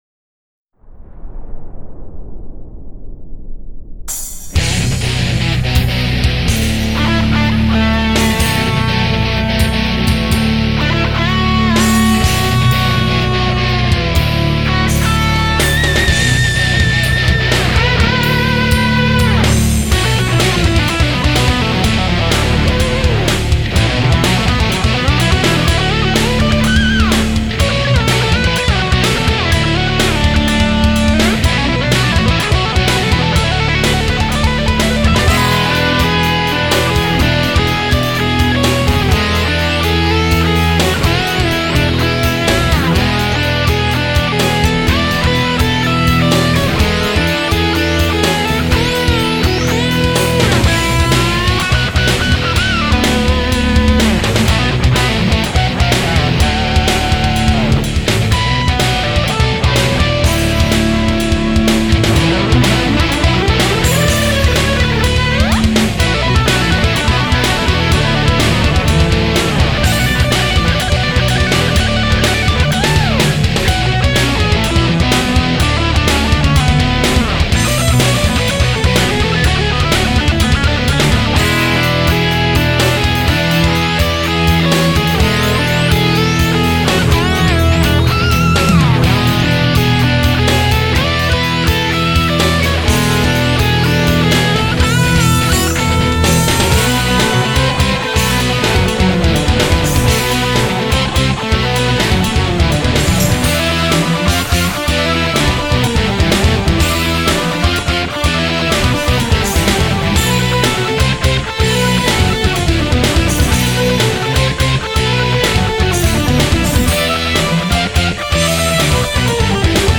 Instrumental, Metal
I began to work again on the song sometime in March 1999 when I started to record the basic riffs.
The song turned out to be rather heavy and also I do more guitar shredding than in my other songs (at least that’s what I think, but judge for yourself). This is also basically the first song where I really use the full tonal range of my Ibanez Universe 7-string (going deep down ;-)).